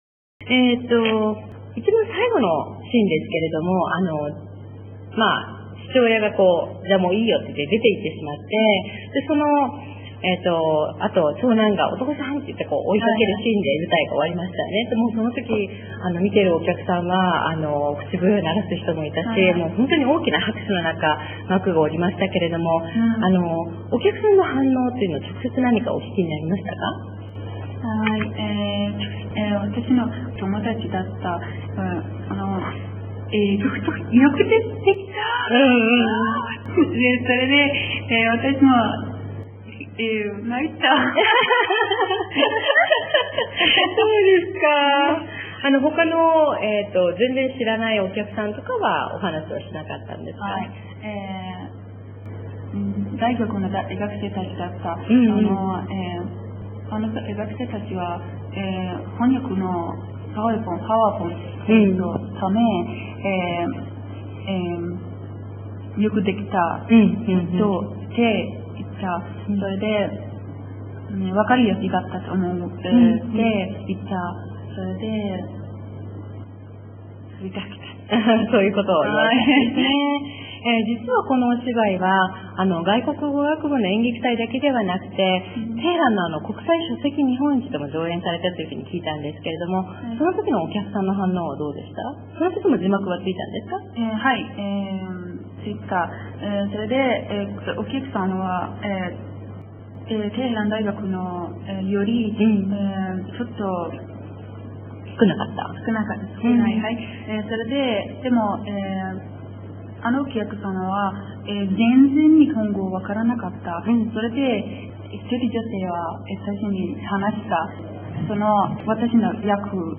テヘラン大学外国語学部4年生へのインタビュー（３）